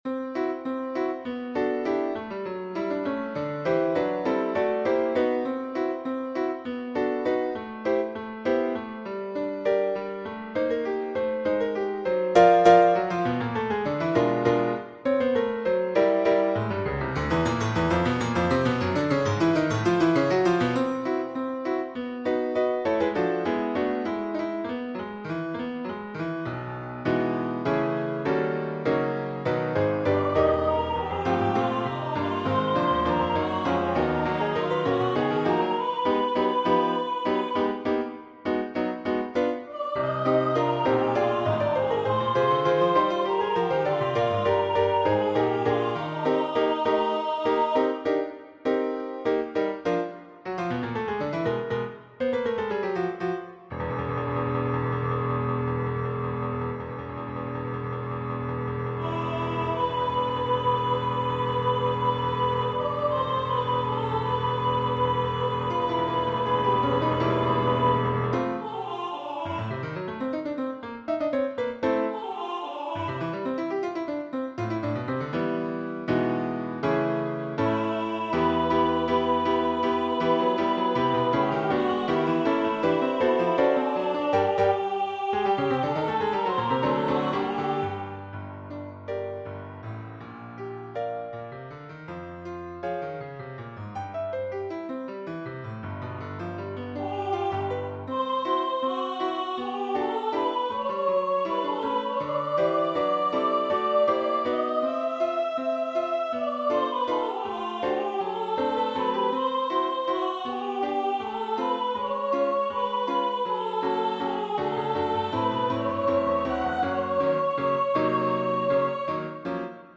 Lewis Carroll Number of voices: 1v Voicing: Unison Genre: Secular, Ballade
Language: English Instruments: Piano
This is a vocal and piano satire playing on the nonsensical character of the poem itself.